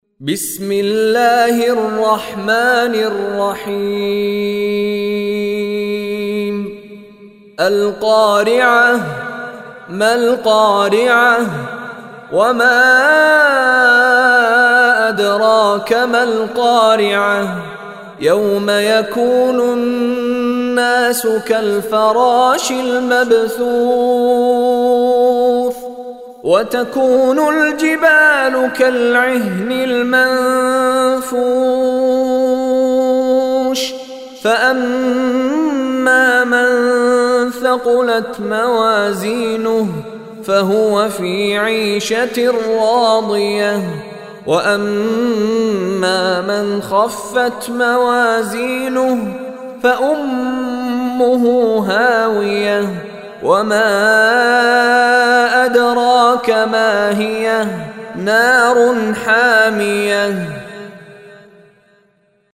Surah Al Qariah Beautiful Recitation MP3 Download By Sheikh Mishary Rashid in best audio quality.